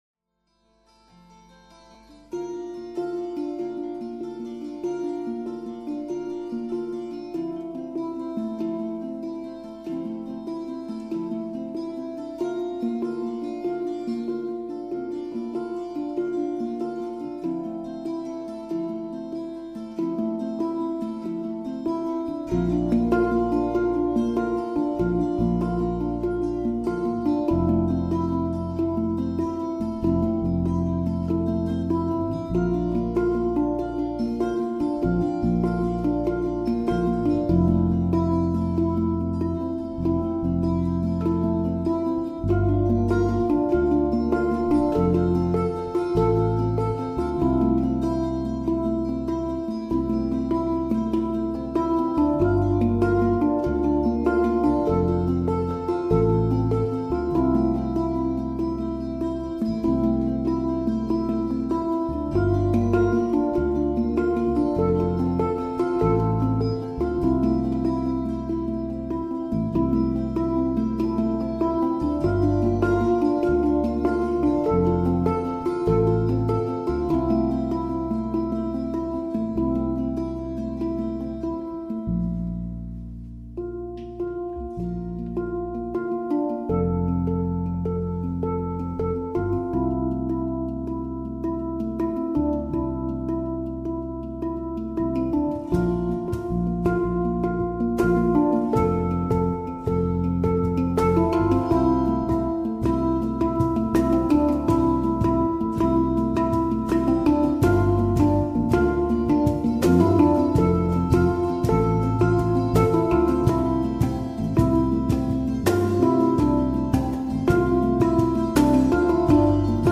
Hang Ensemble